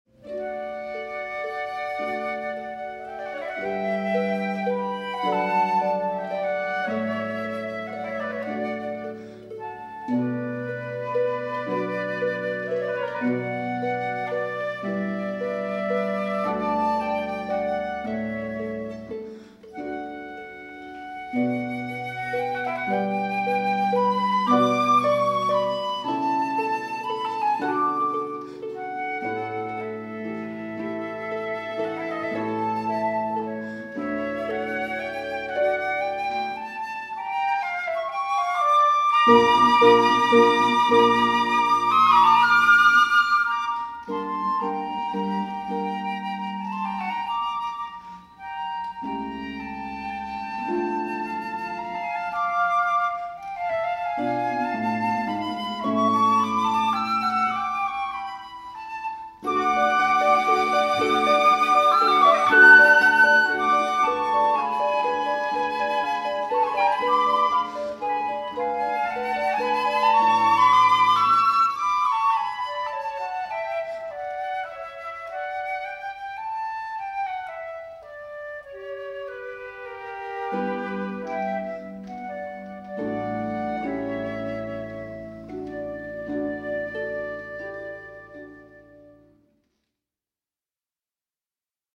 flute et cordes à Port-Royal
Cloître de Port-royal